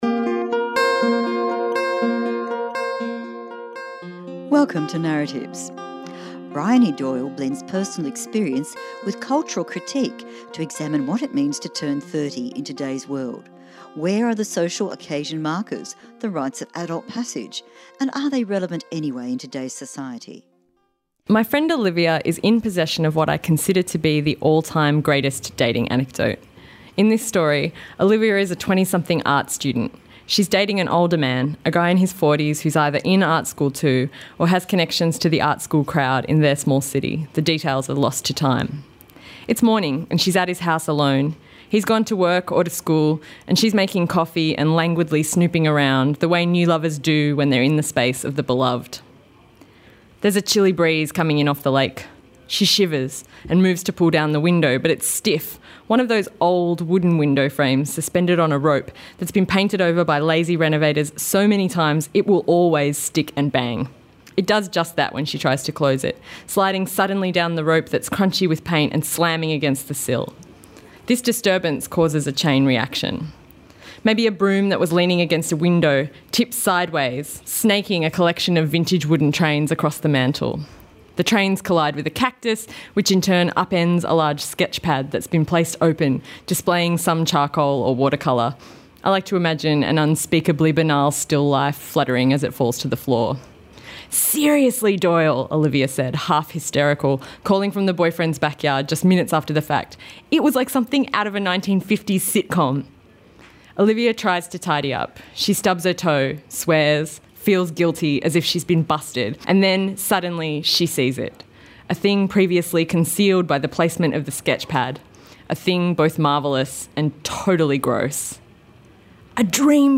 Recorded at the Sydney writers Festival 2017